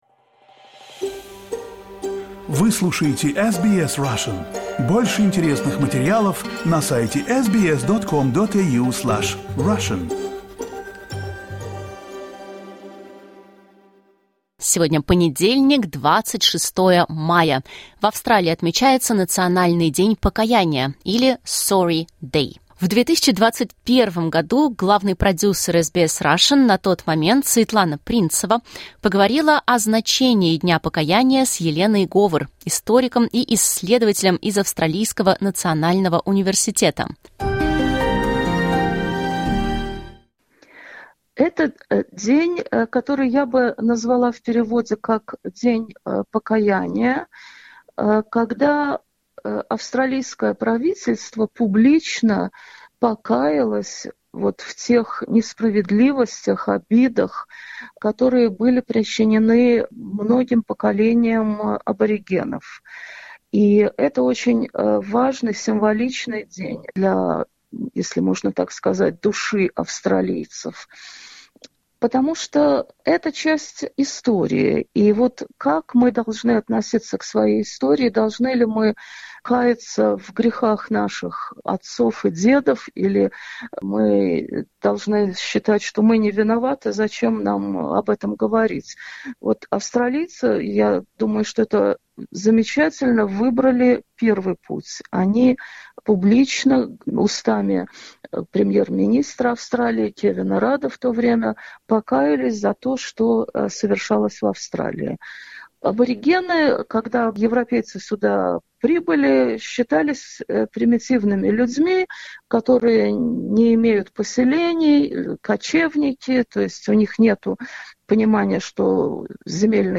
Это интервью было впервые опубликовано 26 мая 2021 года.